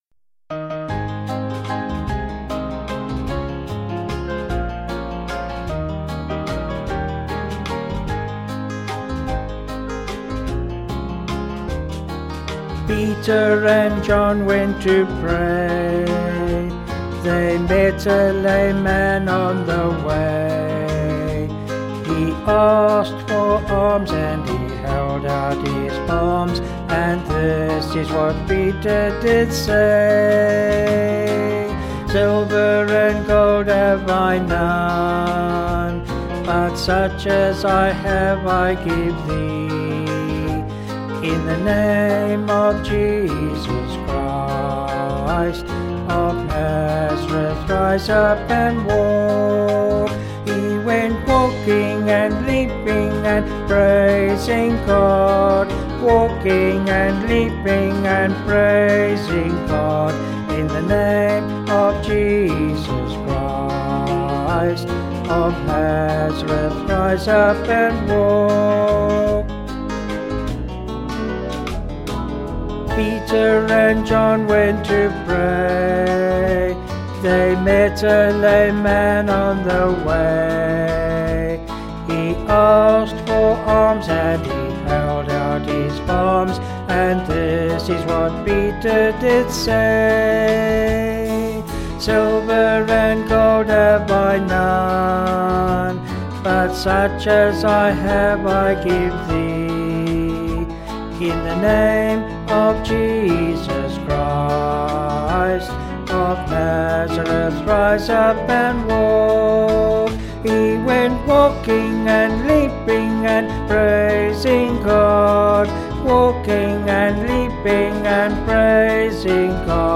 Vocals and Band   264.4kb